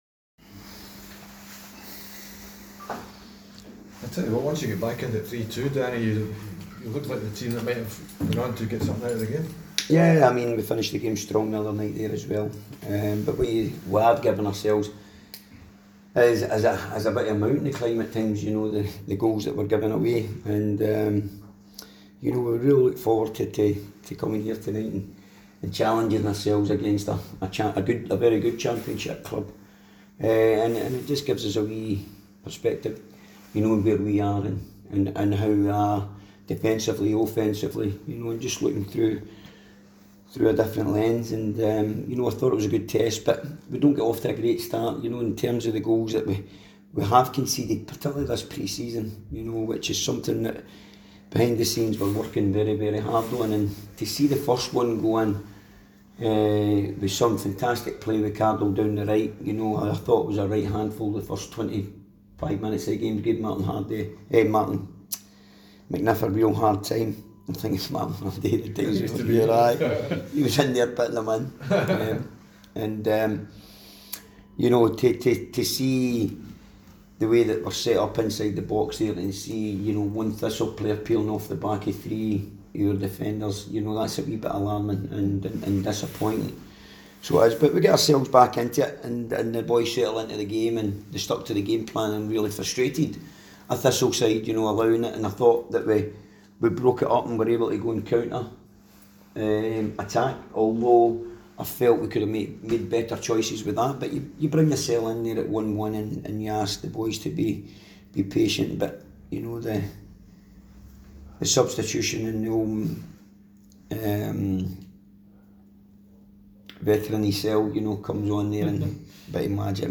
press conference after the Betfred Cup match.